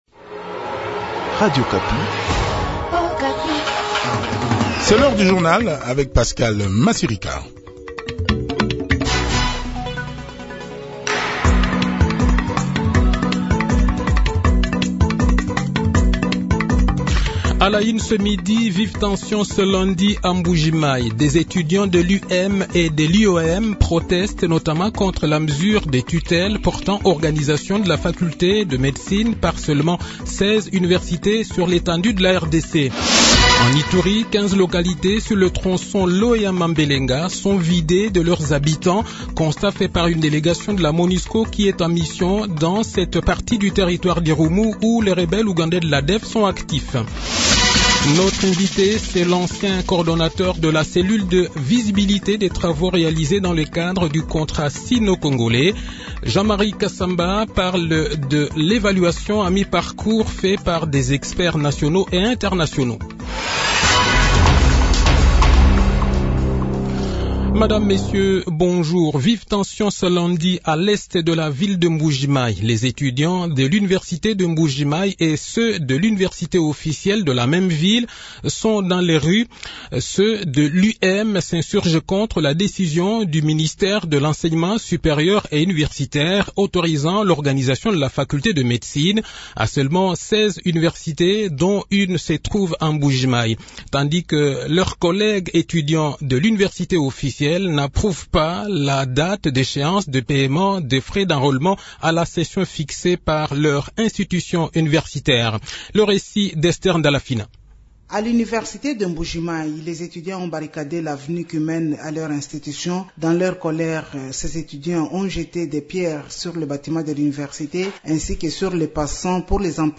Le journal de 12 h, 20 Septembre 2021